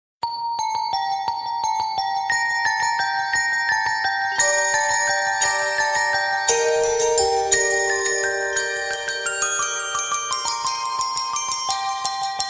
новогодние